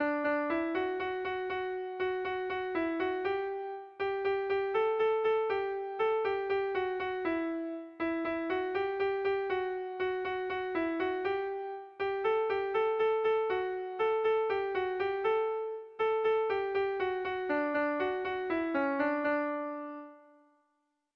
Air de bertsos - Voir fiche   Pour savoir plus sur cette section
Kontakizunezkoa
Hamarreko txikia (hg) / Bost puntuko txikia (ip)
A1BA2DE